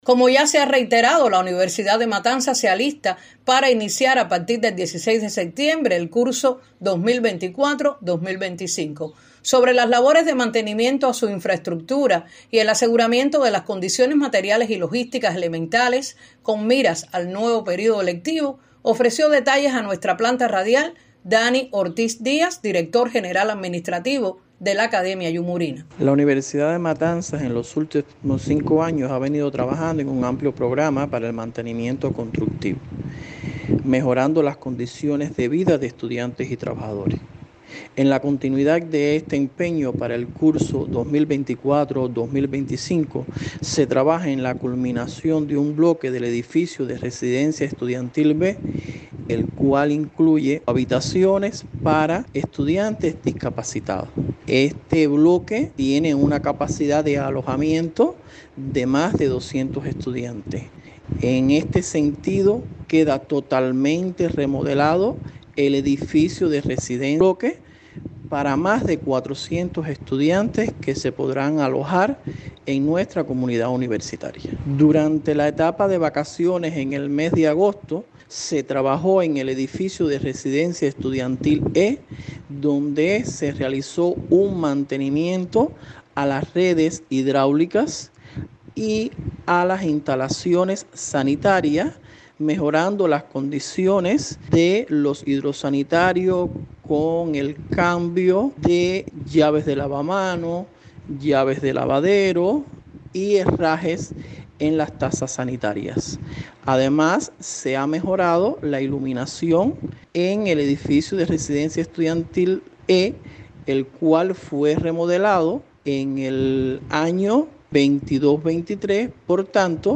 en la entrevista que concedió a Radio 26 a propósito de acercarse la fecha inaugural del venidero curso